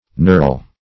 Meaning of nurl. nurl synonyms, pronunciation, spelling and more from Free Dictionary.
nurl.mp3